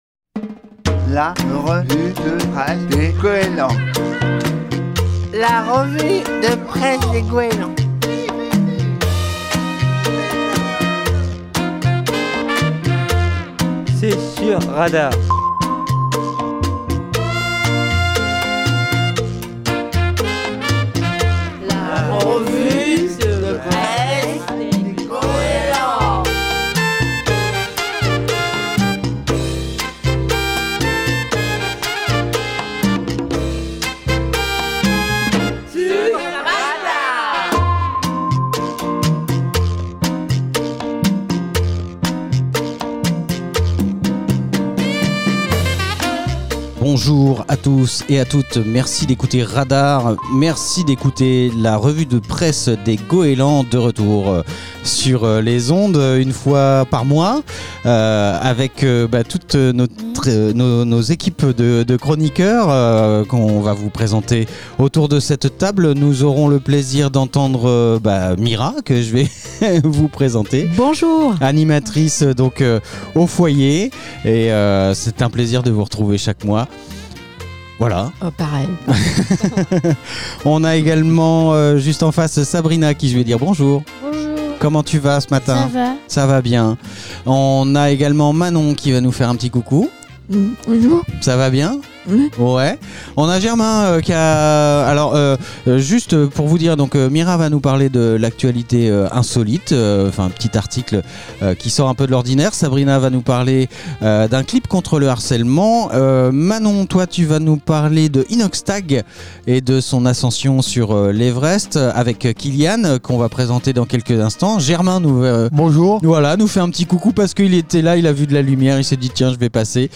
Les usagers du foyer d'activités des Goélands de Fécamp font leur revue de presse tous les 2ème mardis de chaque mois sur Radar